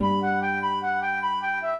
flute-harp
minuet12-7.wav